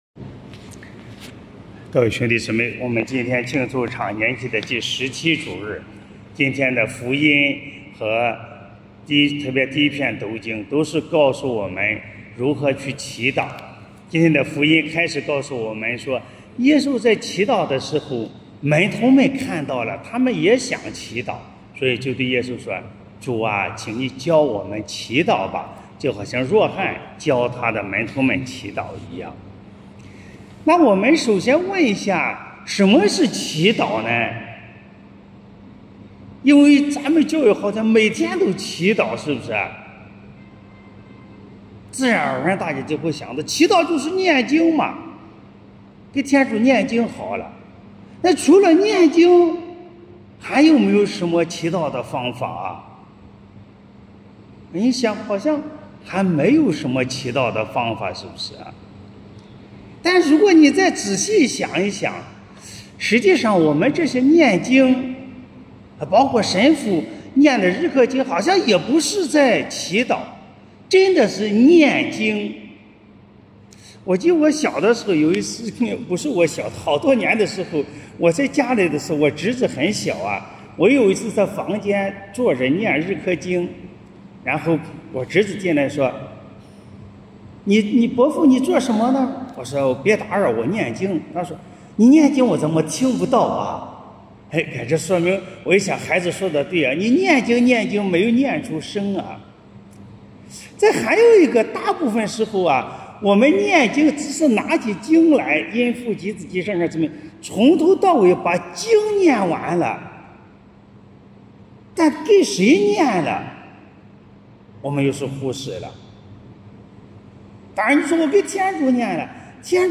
【主日反省】| 祈祷找到天主（丙-常年期第17主日）